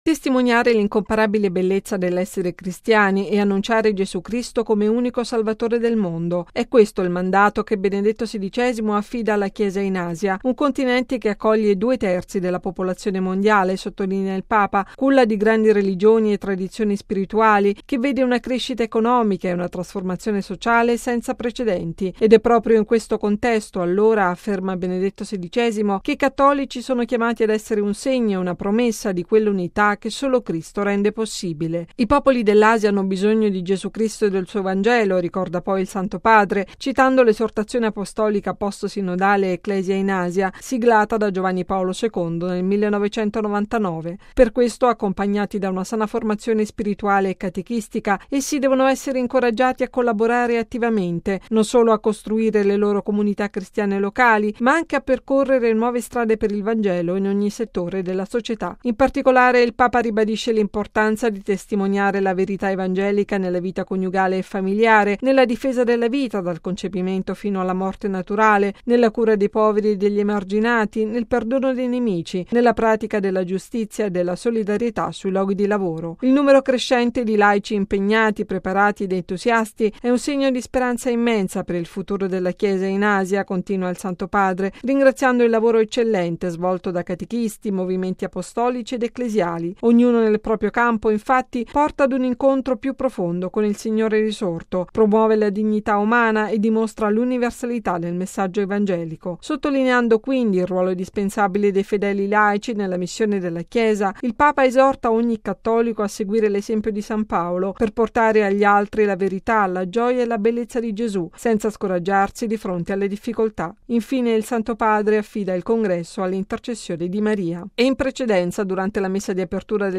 Al centro del documento pontificio anche il richiamo al “ruolo indispensabile dei fedeli laici nella missione della Chiesa”. Il servizio